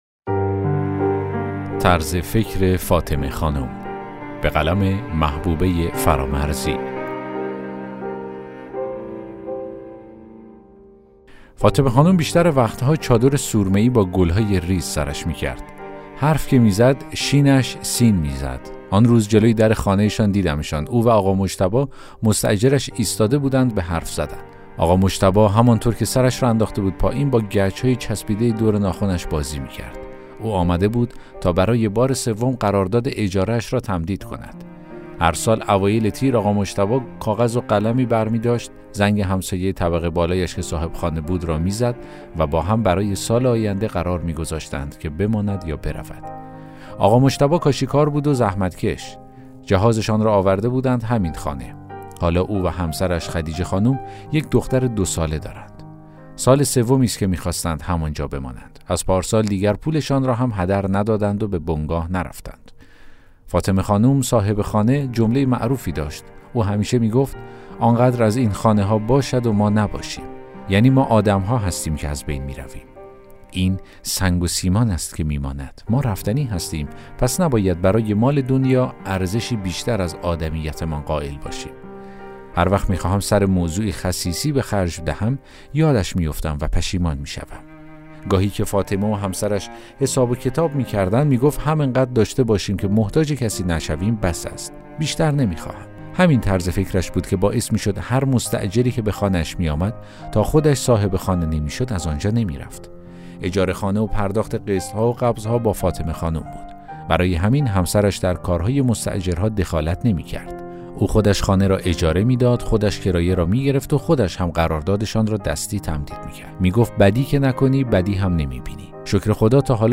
داستان صوتی: طرز فکر فاطمه خانم